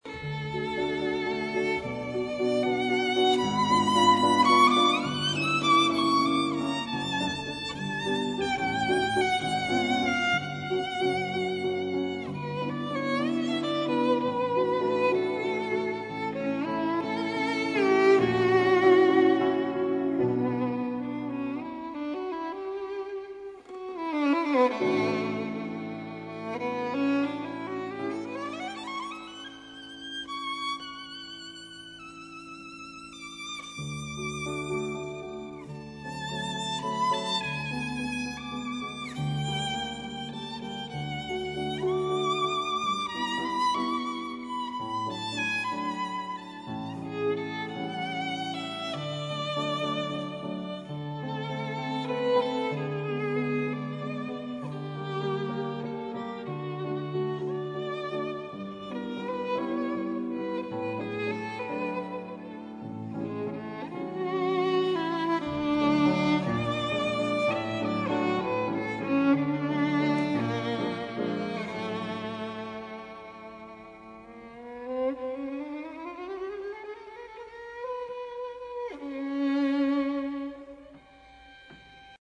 Занятие шестое. Струнные инструменты
skripka.mp3